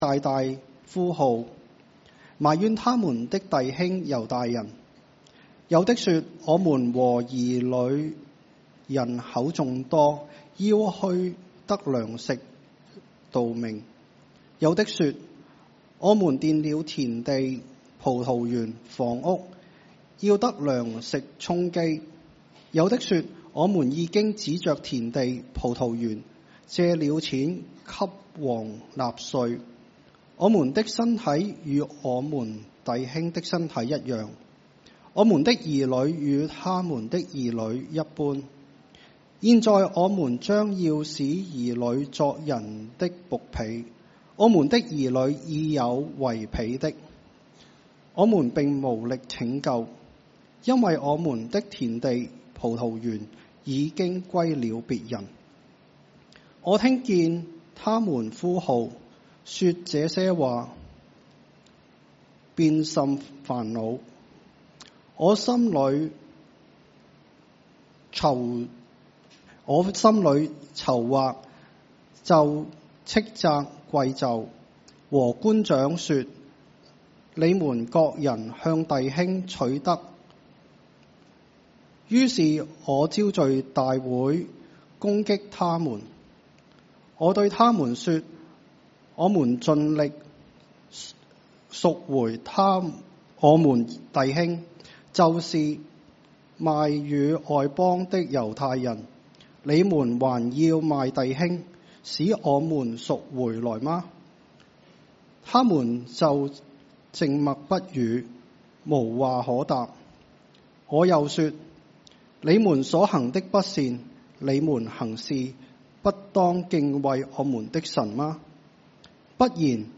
Cantonese 3rd Service, Chinese Category